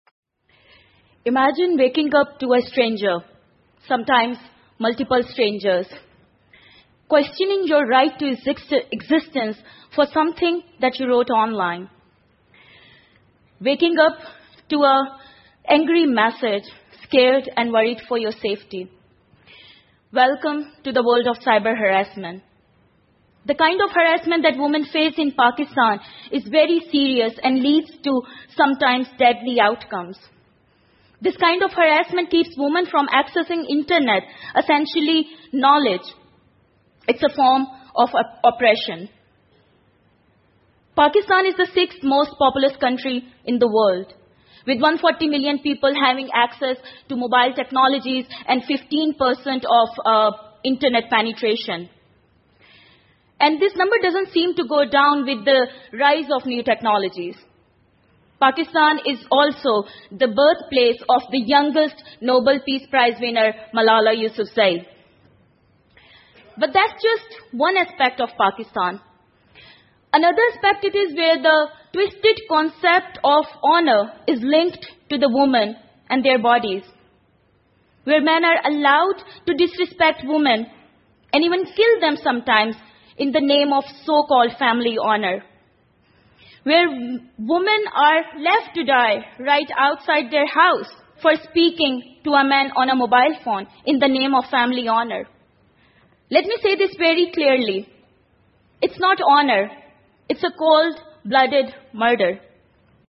TED演讲:巴基斯坦女性如何夺回上网的权力() 听力文件下载—在线英语听力室